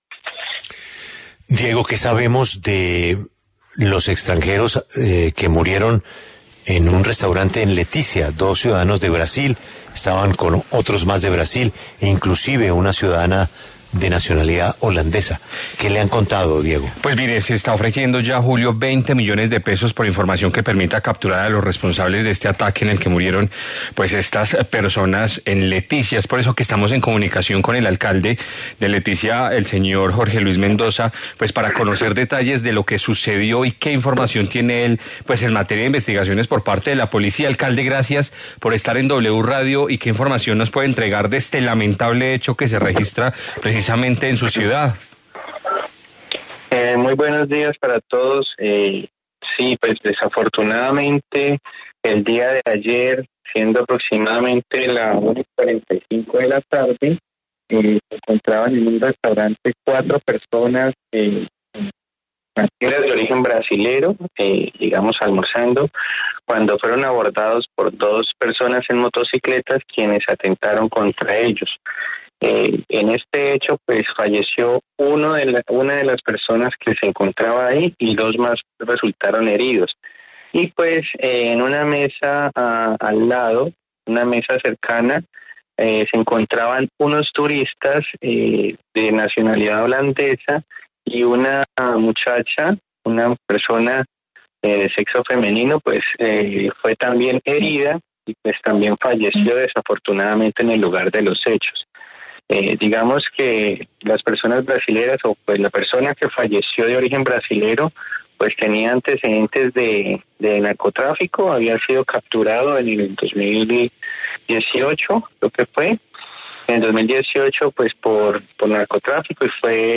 En diálogo con La W, el alcalde de Leticia, Jorge Luis Mendoza, se pronunció sobre la muerte de dos personas en un hecho sicarial.